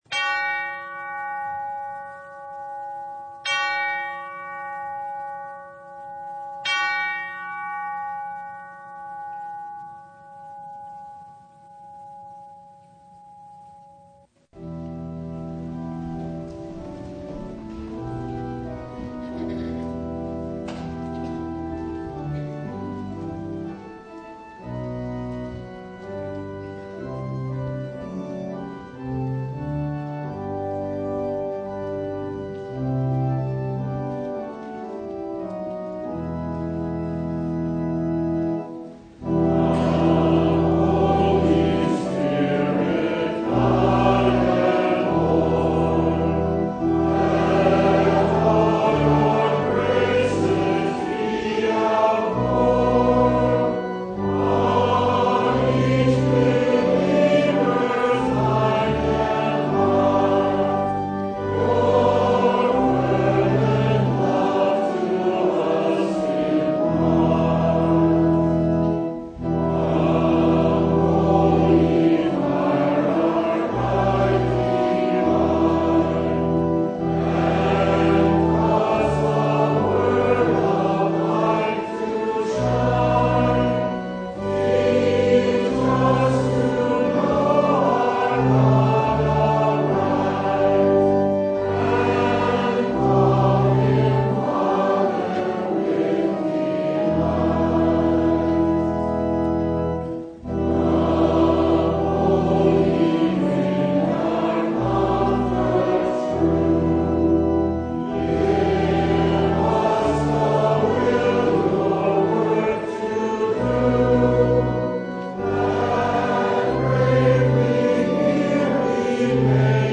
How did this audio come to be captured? Passage: Matthew 22:1-14 Service Type: Sunday